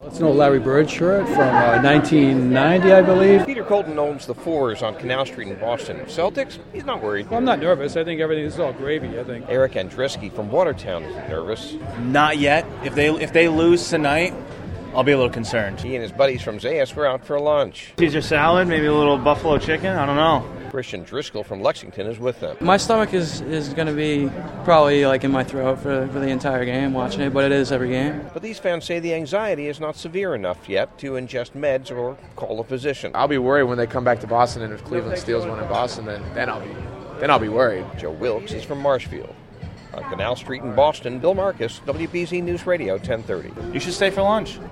(BOSTON WBZ-AM, MAY 21) – GAME FOUR OF THE EASTERN CONFERENCE BEST OF SEVEN NBA CHAMPIONSHIP IS TONIGHT IN CLEVELAND WITH THE CAVALIER’S BRAGGING A BIT OF MOMENTUM.